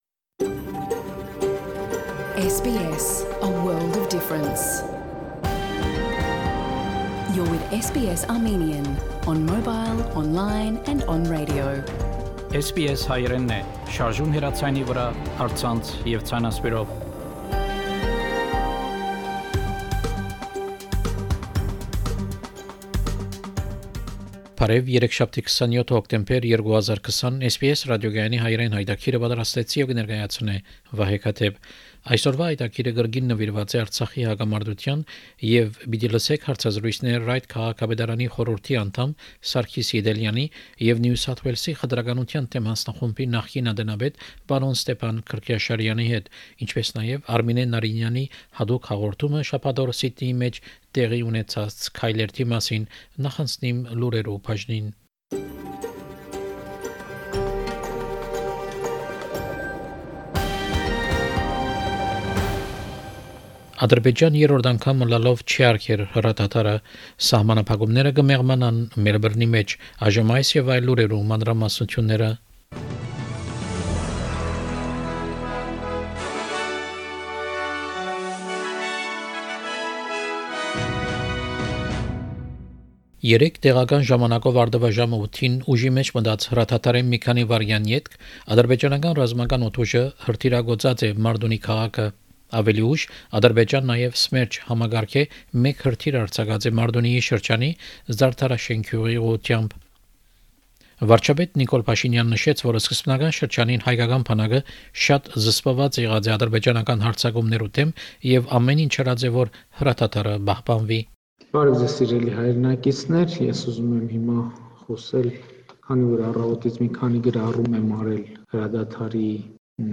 SBS Armenian news bulletin – 27 October 2020
SBS Armenian news bulletin from 27 October 2020 program.